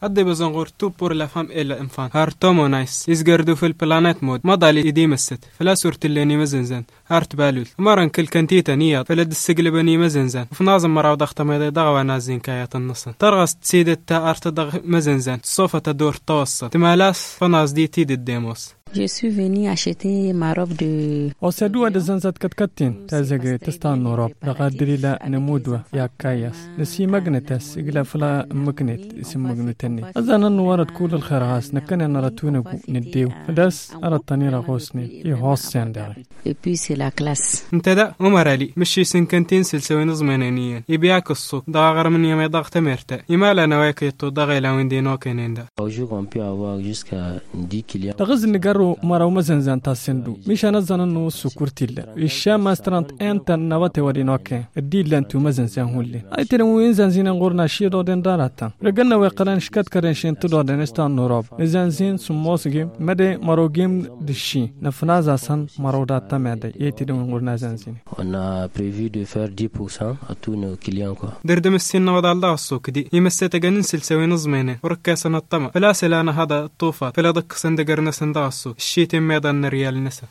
a sillonné quelques Magasins de prêt à porter dans la ville de Niamey.